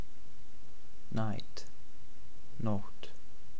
English word Icelandic word Spoken comparison